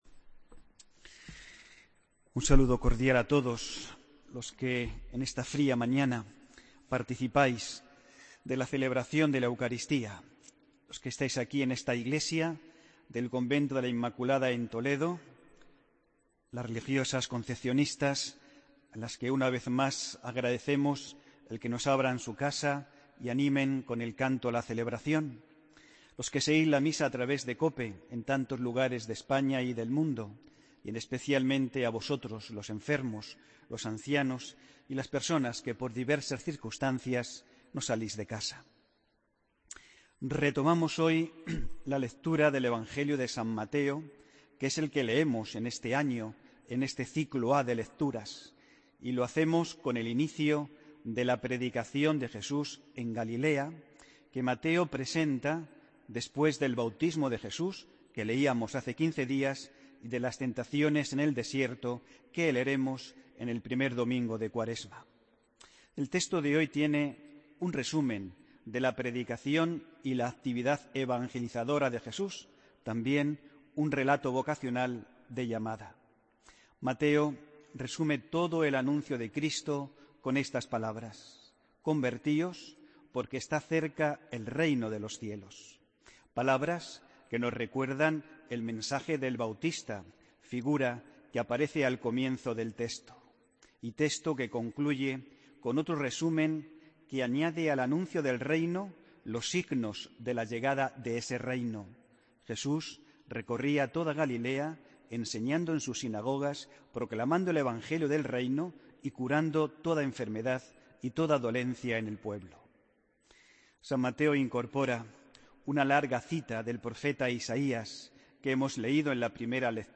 AUDIO: Homilía del domingo 22 de enero de 2017